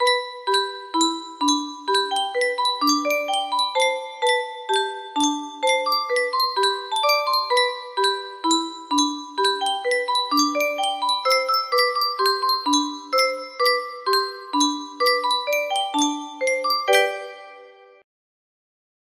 Yunsheng Music Box - The U. of M. Rouser 1068 music box melody
Full range 60